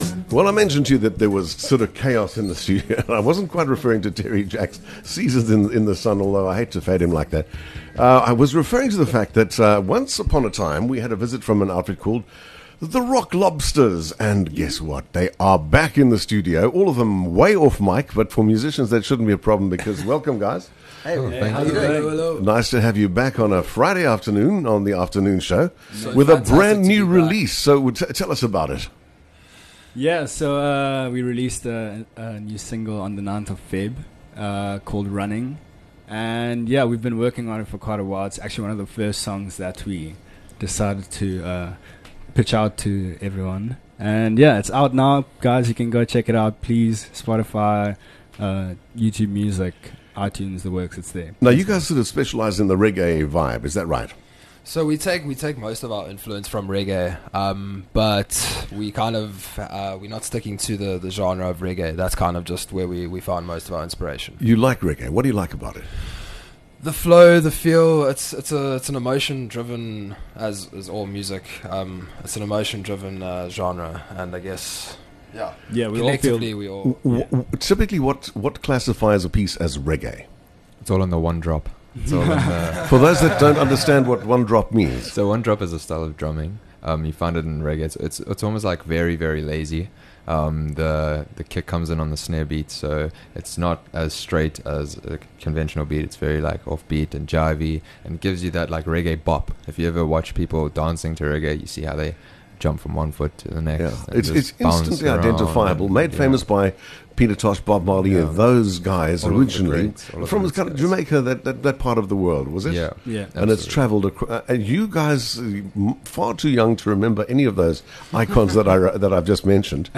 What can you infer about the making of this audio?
In studio to launch another great track - take a listen...